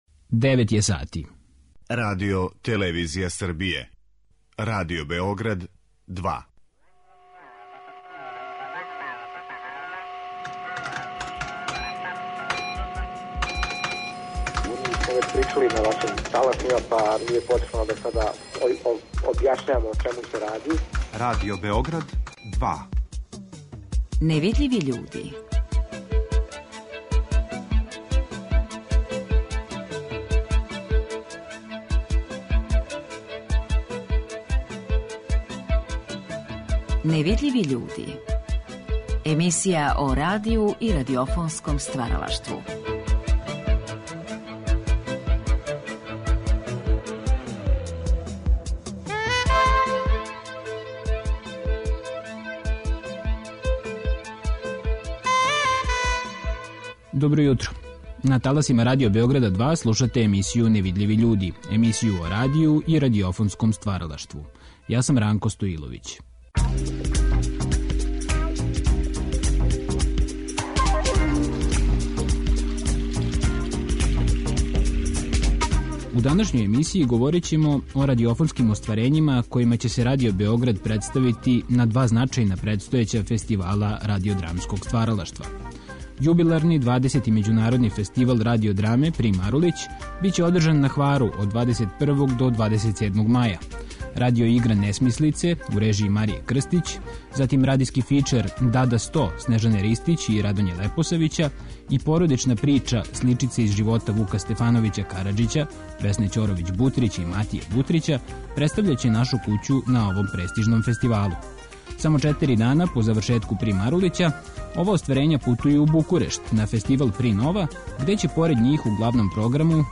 У Невидљивим људима слушамо ауторе и фрагменте дела која су успешно прошла предселекцију на овим фестивалима.